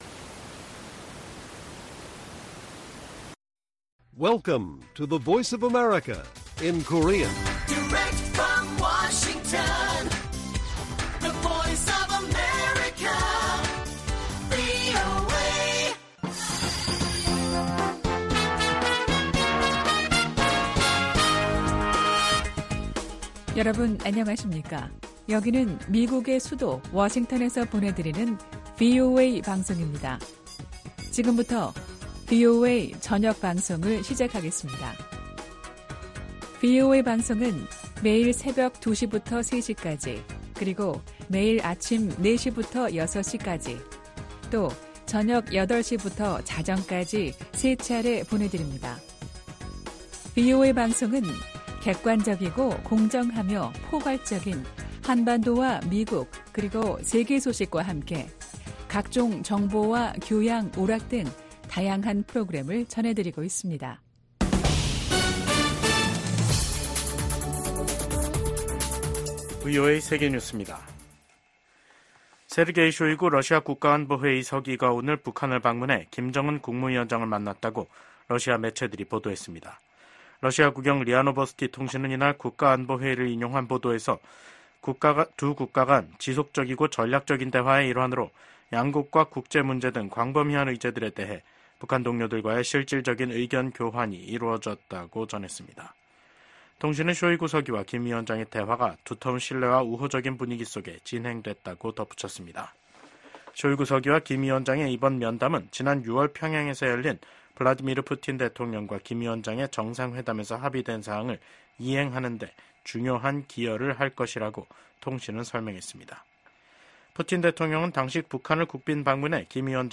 VOA 한국어 간판 뉴스 프로그램 '뉴스 투데이', 2024년 9월 13일 1부 방송입니다. 북한이 핵탄두를 만드는 데 쓰이는 고농축 우라늄(HEU) 제조시설을 처음 공개했습니다. 미국과 리투아니아가 인도태평양 지역에 대한 고위급 대화를 개최하고 러시아의 북한제 탄도미사일 사용을 비판했습니다.